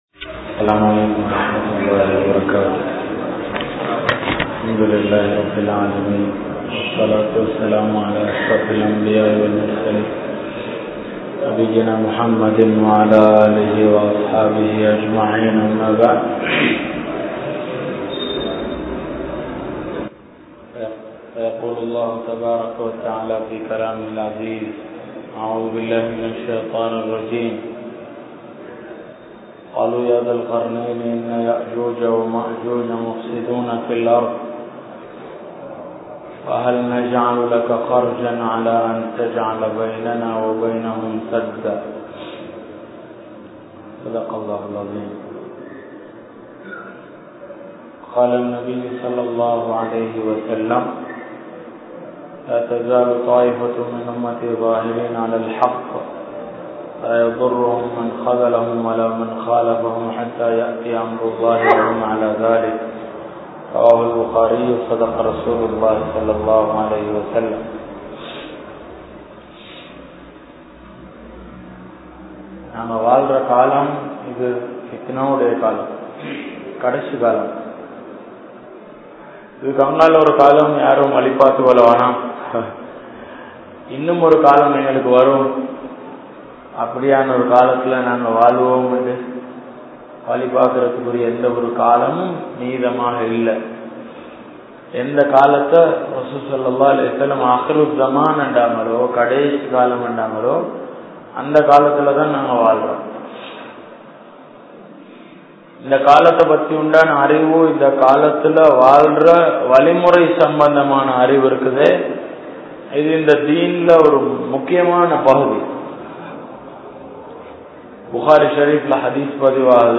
Naam Vaalum Kaalam Iruthi Kaalama? (நாம் வாழும் காலம் இறுதி காலமா?) | Audio Bayans | All Ceylon Muslim Youth Community | Addalaichenai
Galle, Khilir Masjidh